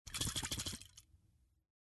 Звуки аэрозоля
Спрей для носа встряхните перед применением